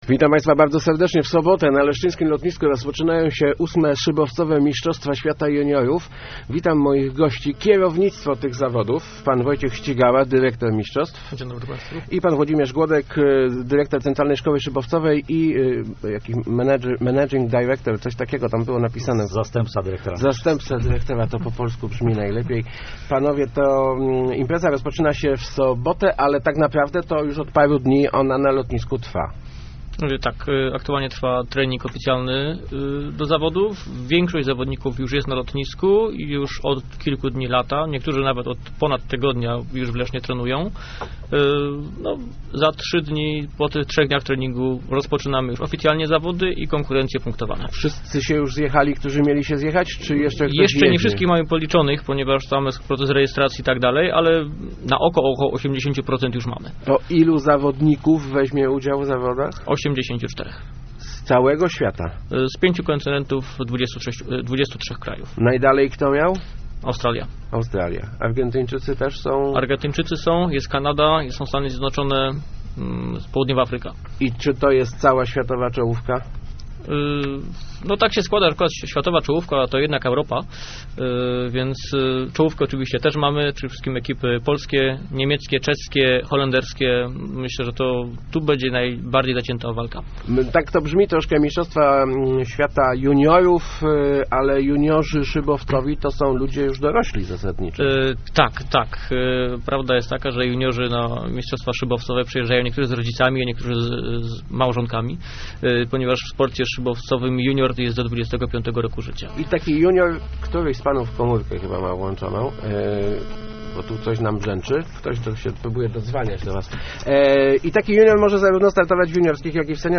Rozmowach Elki